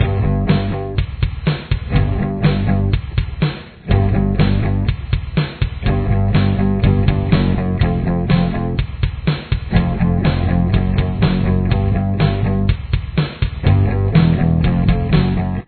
Verse